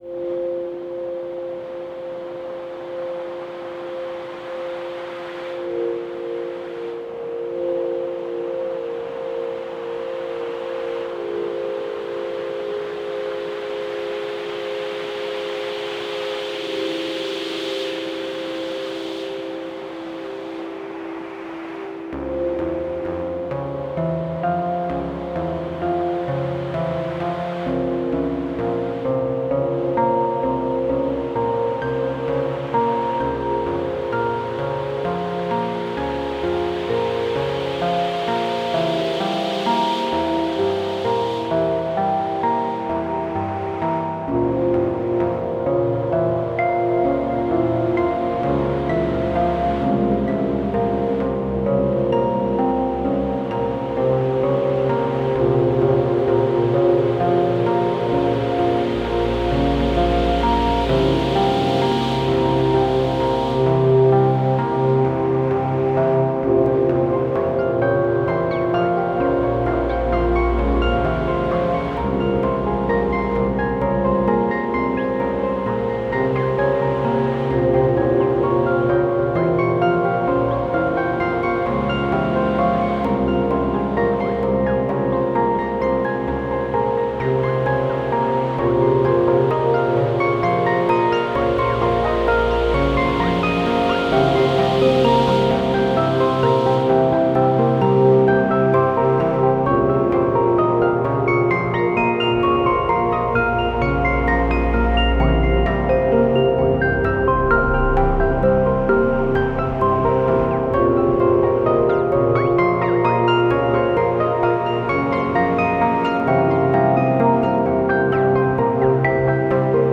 synthétiseurs
Cette musique atmosphérique et mélancolique